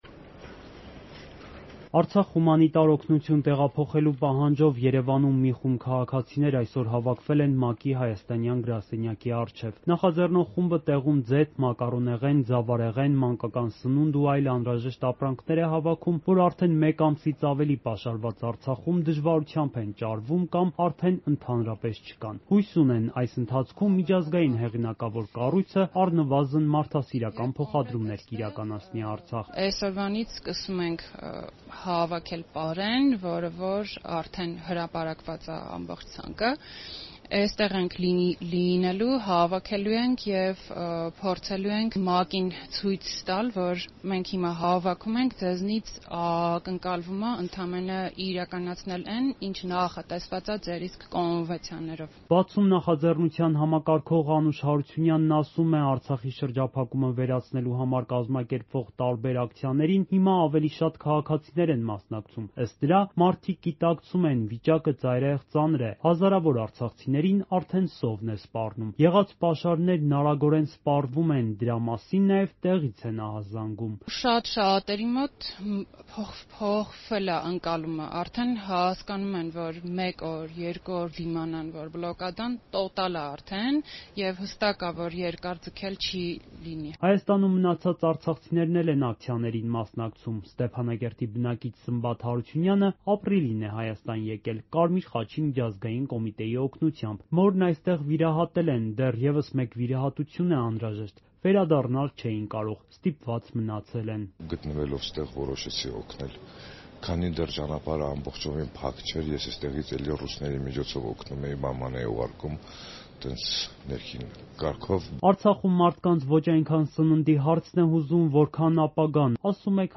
Արցախ հումանիտար օգնություն տեղափոխելու պահանջով ակցիա՝ Երևանում ՄԱԿ-ի գրասենյակի առջև
Ռեպորտաժներ